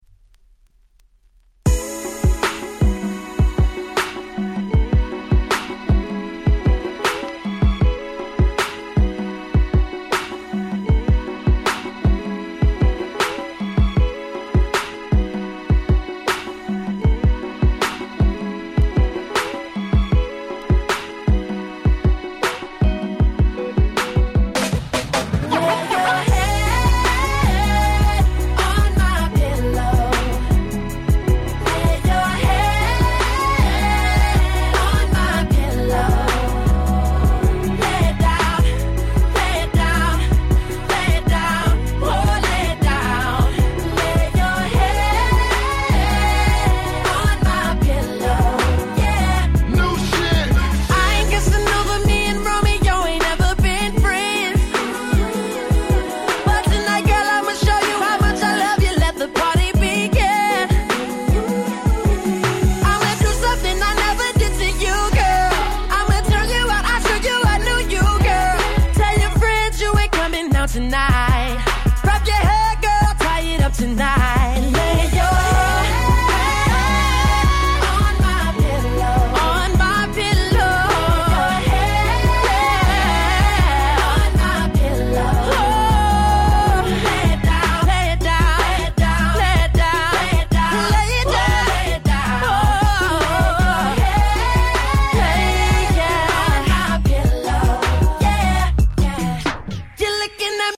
10' Super Hit R&B !!